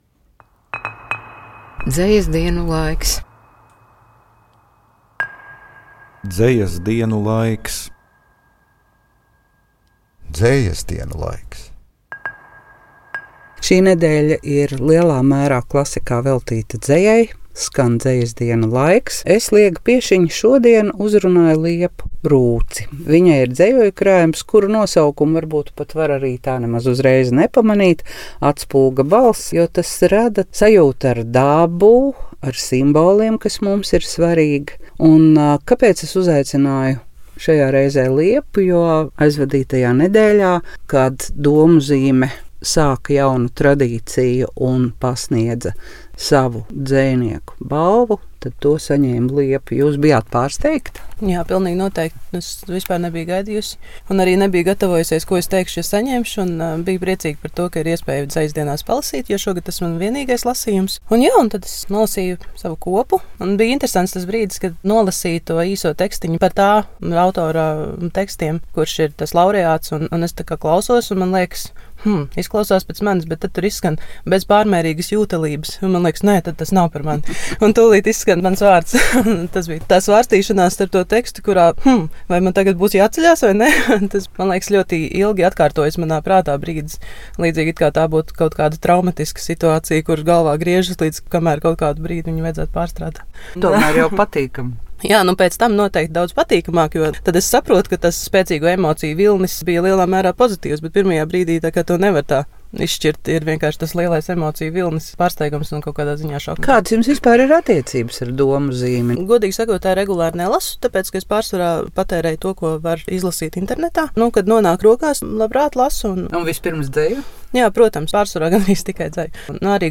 Dzeja – telpa savai patiesībai. Intervija